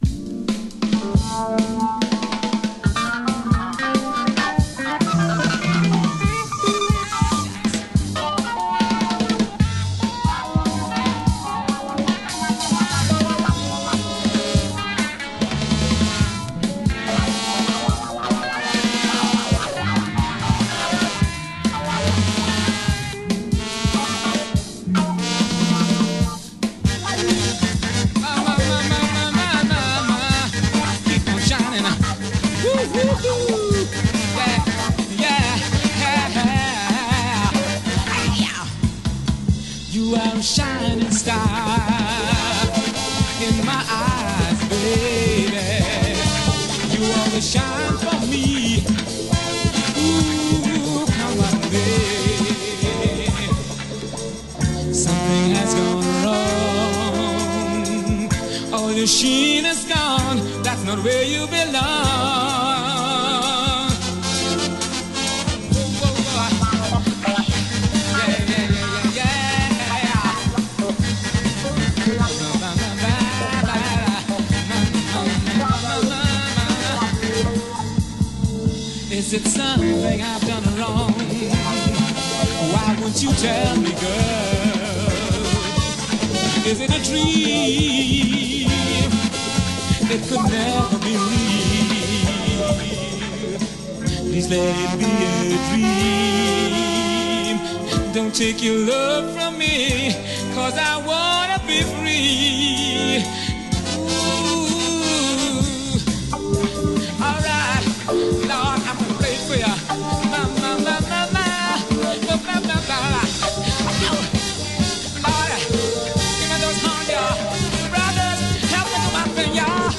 Reggae, caribbean sound and funky soul tunes…